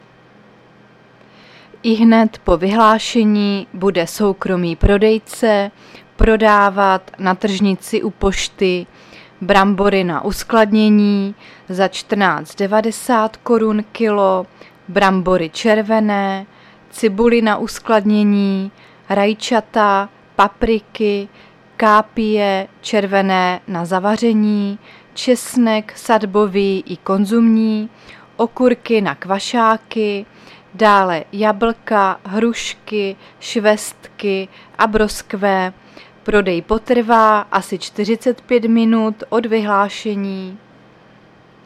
Záznam hlášení místního rozhlasu 14.9.2023